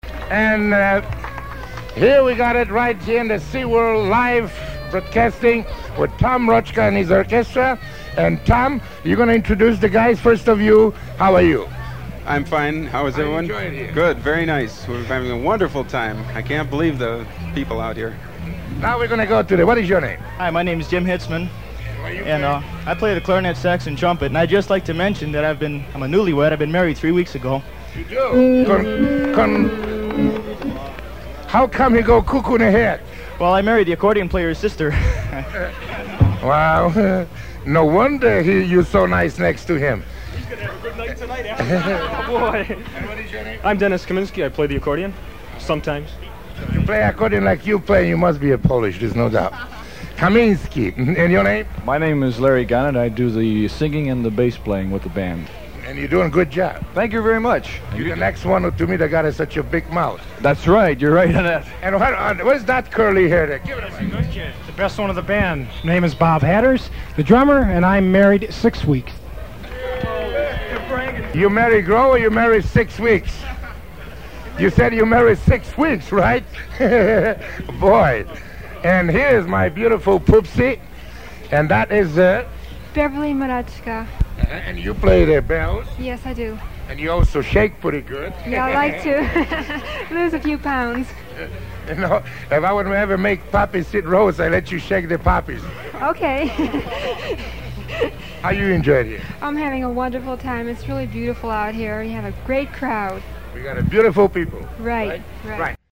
Introduces Band